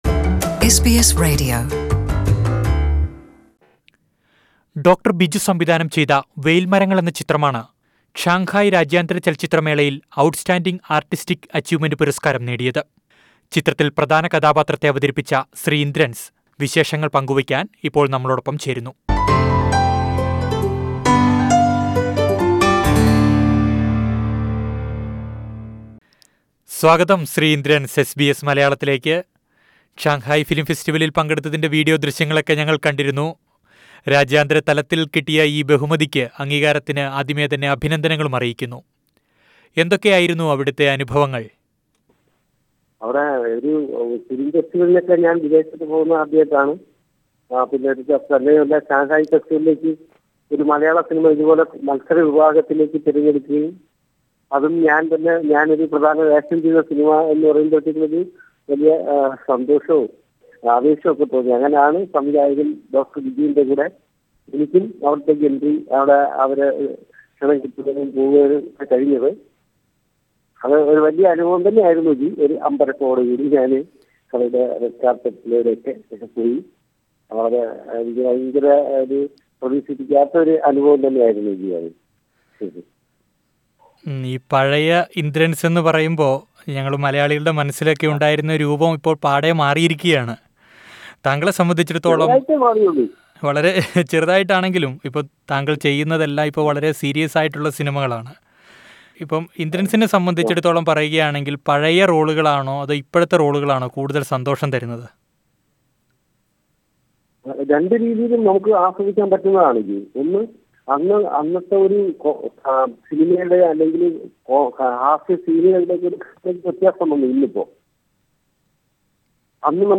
After attending the Shanghai International Film Festival, Malayalam actor Indrans talks to SBS Malayalam about his film career.